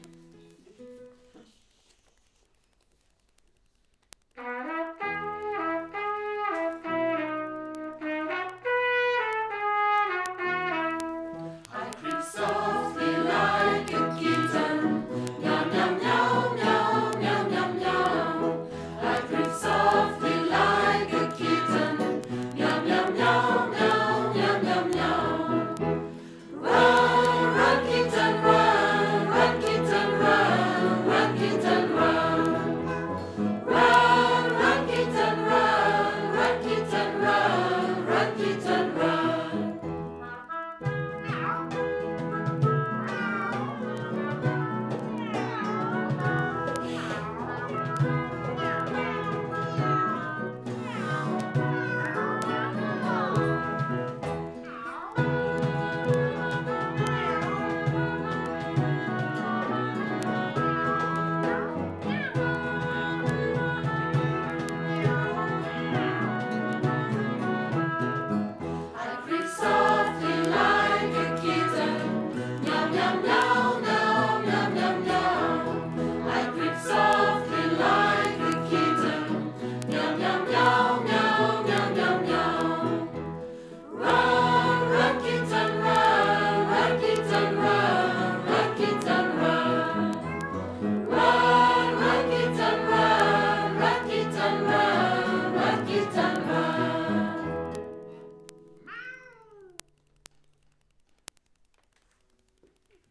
Un chant en anglais pour jouer avec sa voix et avec le tempo